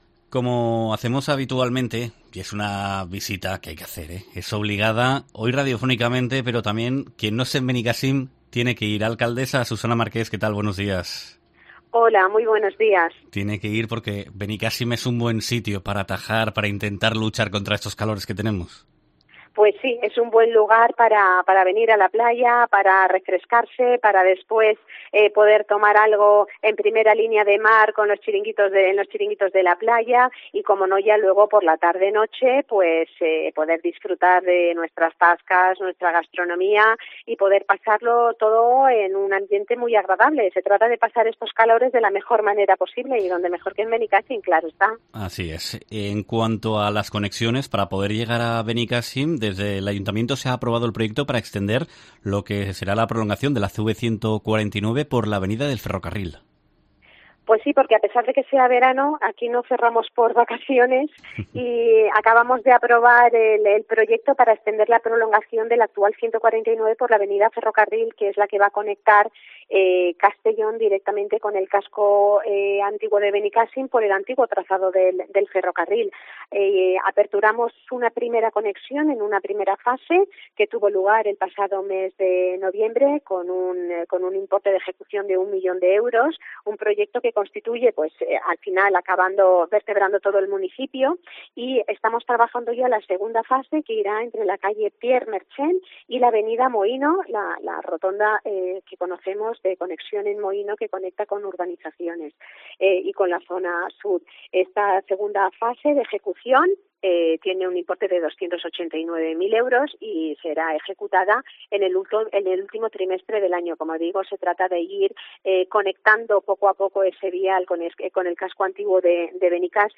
AUDIO: Benicàssim trabaja en la mejora de la accesibilidad para facilitar los desplazamientos, como explica en COPE la alcaldesa, Susana Marqués
Entrevista